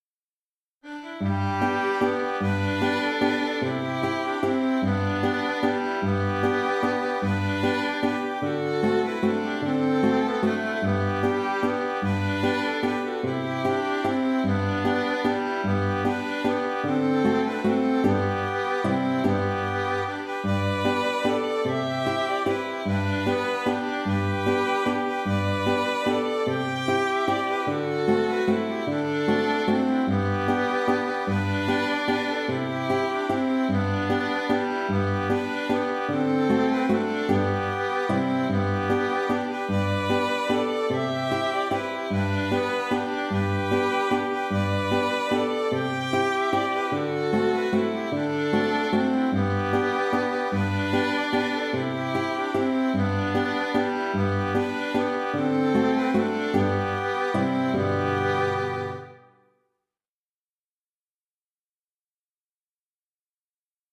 Valse country/écossaise
C’est en contemplant un clair de lune qu’une mélodie aux couleurs country s’est formée dans ma tête. Il y a sans doute quelque emprunt au folk américain dans le thème, mais il reste néanmoins une composition dans l’ensemble.